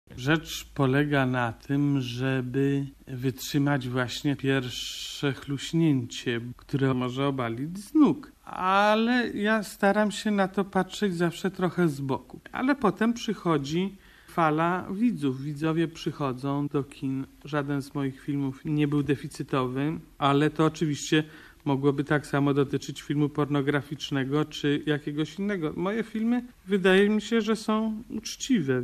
90 lat temu urodził się Stanisław Bareja [ARCHIWALNE WYPOWIEDZI]